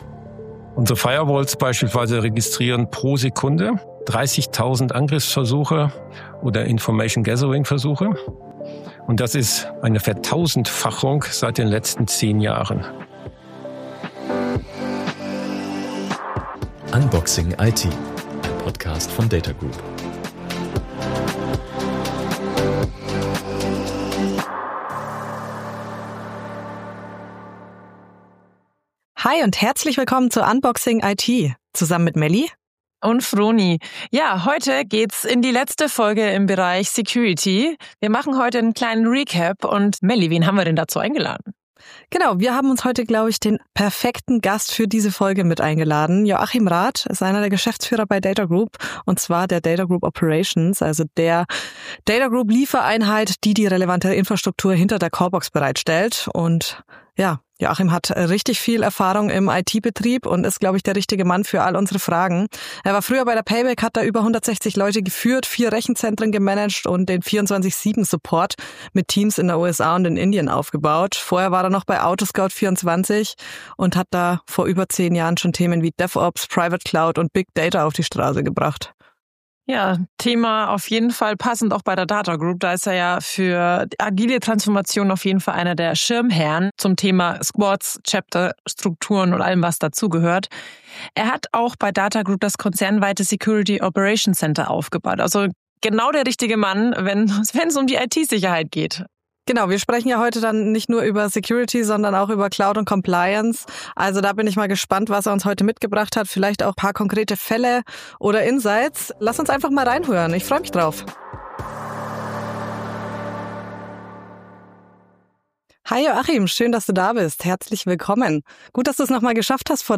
Im Gespräch geht es um konkrete Bedrohungen, technische Learnings, strategische Prioritäten. Und darum, wie KI, Cloud und Compliance in der IT-Sicherheit zusammenspielen müssen.